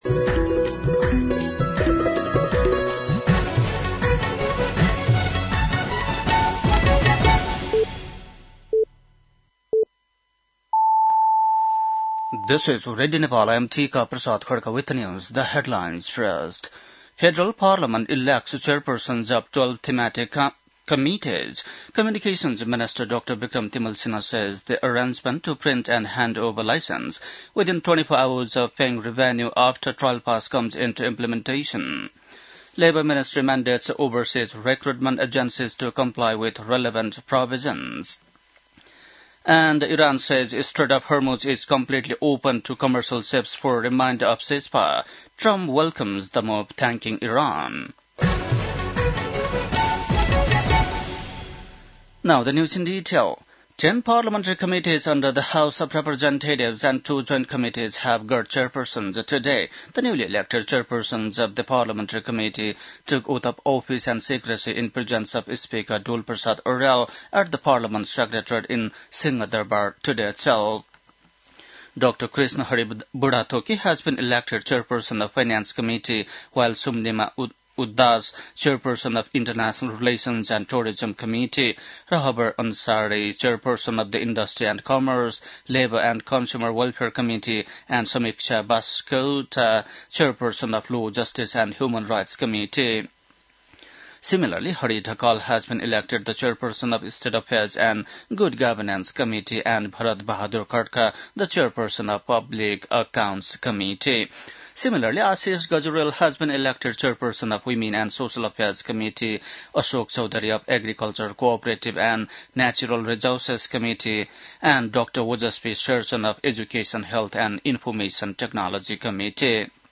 बेलुकी ८ बजेको अङ्ग्रेजी समाचार : ४ वैशाख , २०८३